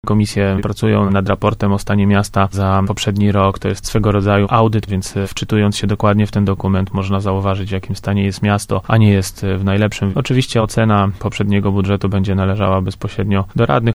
Mówi prezydent Tarnobrzega Łukasz Nowak.